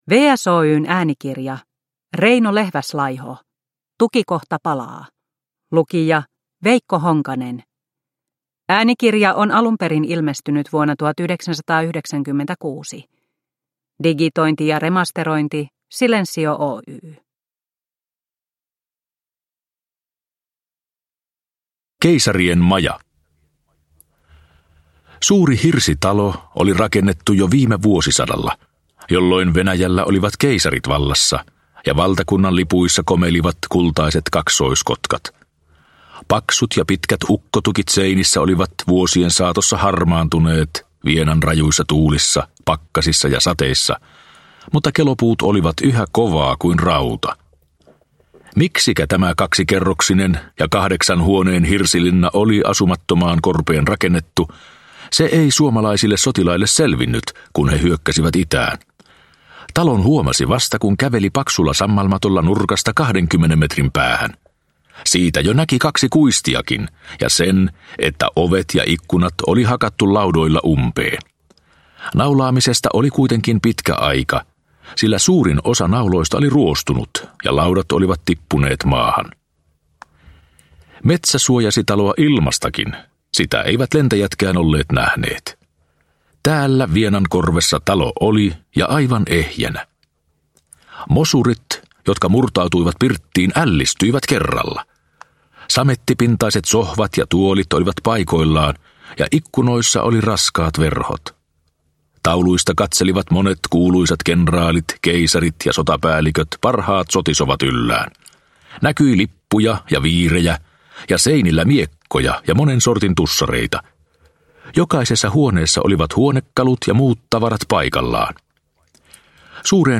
Tukikohta palaa – Ljudbok – Laddas ner